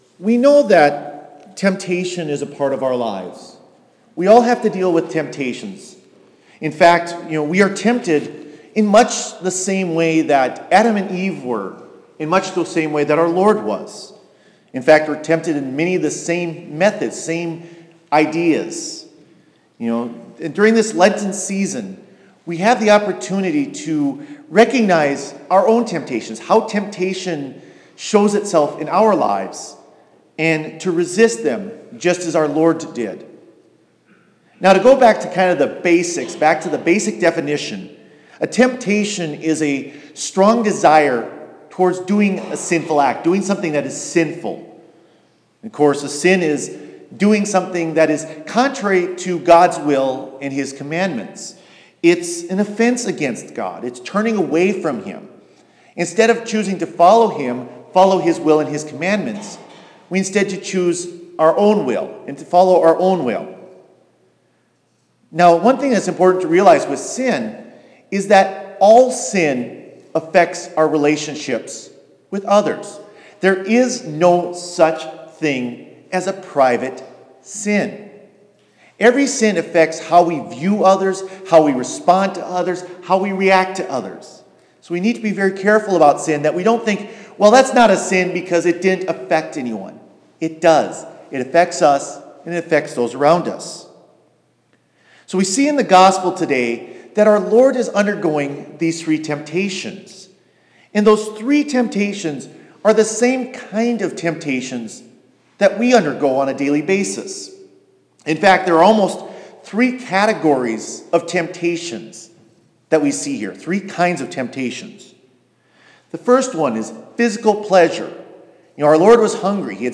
Homily for the First Sunday of Lent